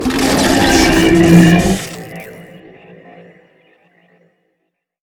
combat / ENEMY / droid
bighurt1.wav